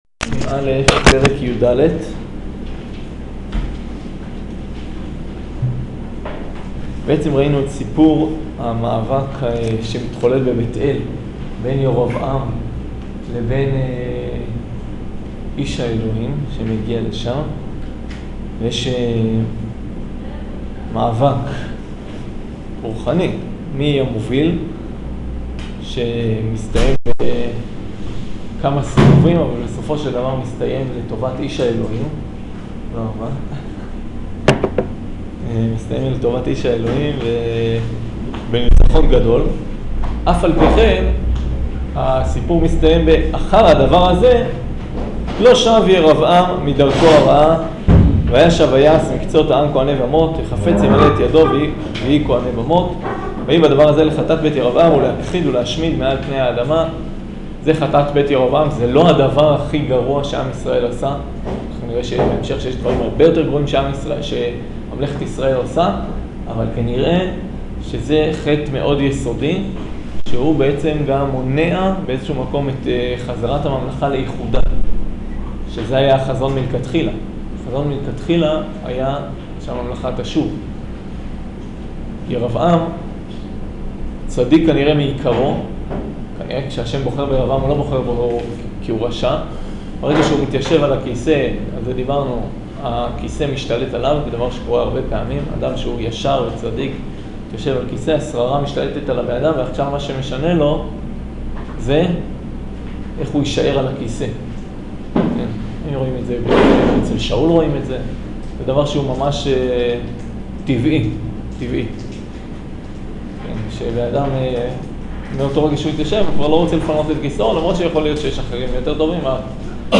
שיעור פרק יד